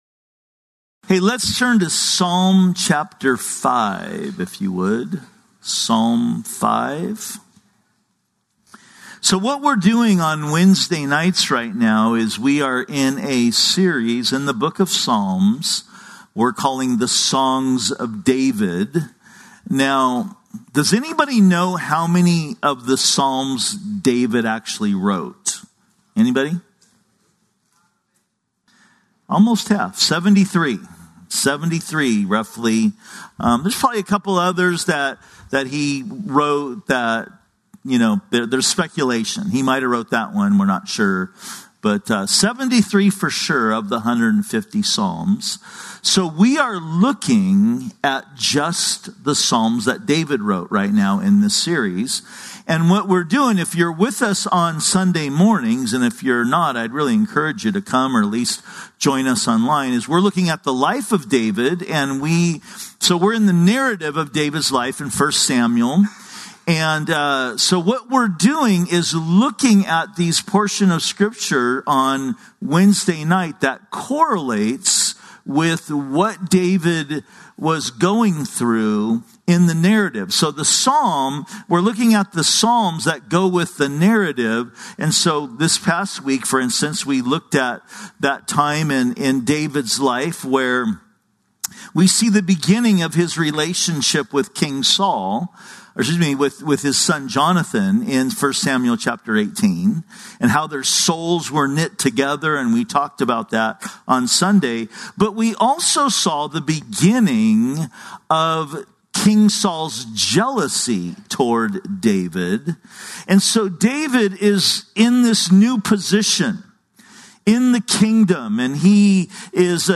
Wednesday Bible studies